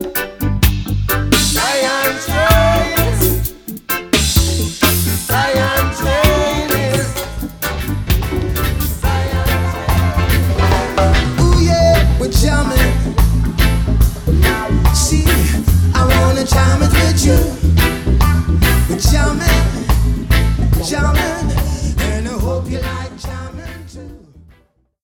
• Tip: If the tempo of rhythmic tracks are greatly different (as in this demonstration) it will be difficult to achieve a really smooth mix, but try and get the beats to match in the centre of the fade.
Here is the finished cross-fade that was used in this demonstration:
The fade shape is the “classic” raised cosine shape (an “S” curve or “sine” fade, give or take 180 degrees)